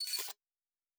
Additional Weapon Sounds 3_2.wav